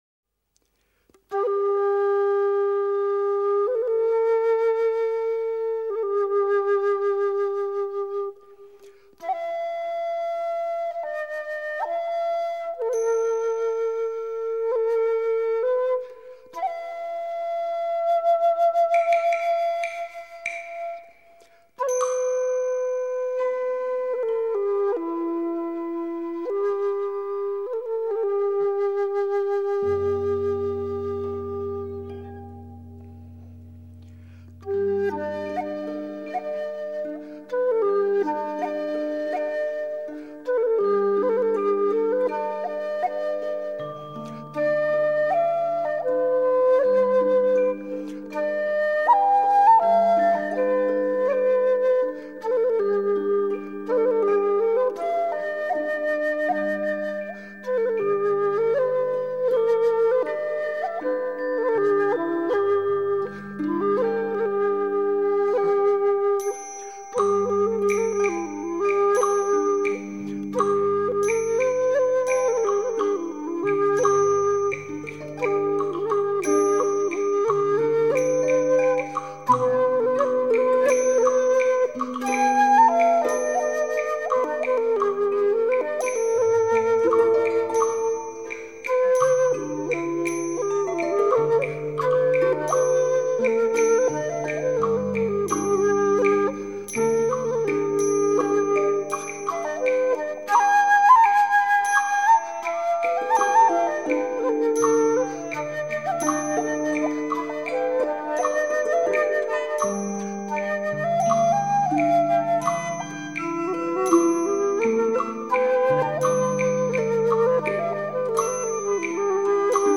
箫
笙、箜篌及打击乐伴奏